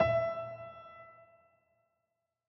🌲 / AfterStory Doki Doki Literature Club game mod_assets sounds piano_keys
E5.ogg